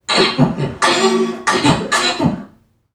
NPC_Creatures_Vocalisations_Robothead [35].wav